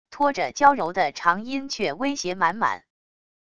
拖着娇柔的长音却威胁满满wav音频